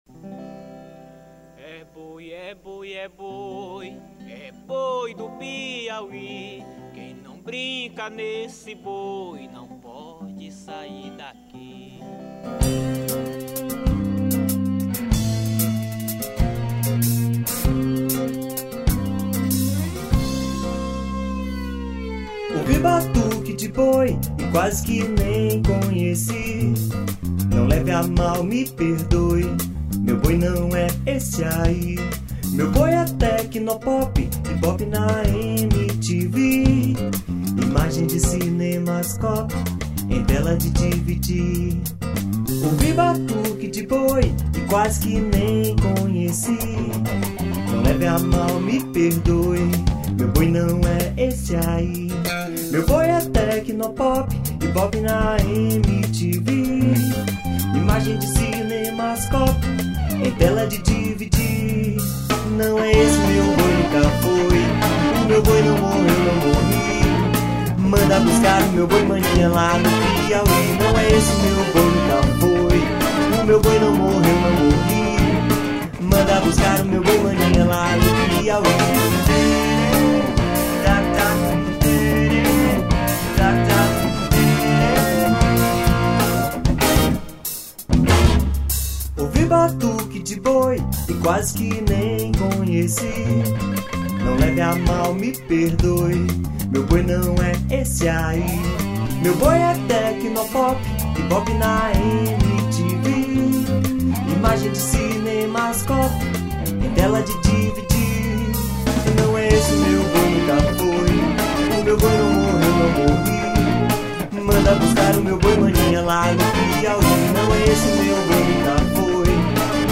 174   03:30:00   Faixa: 1    Rock Nacional
Violao Acústico 6, Voz
Baixo Elétrico 6
Bateria
Guitarra
Teclados
Sax Soprano
Trompete
Trombone de Vara